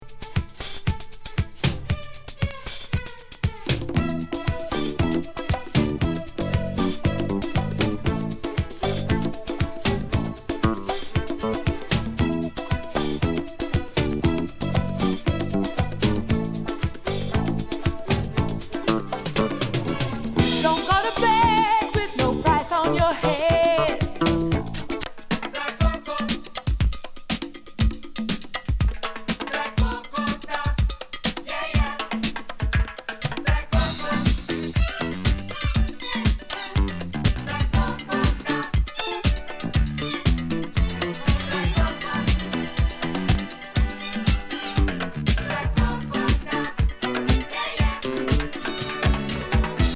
トロピカル・ブレイキン・ディスコ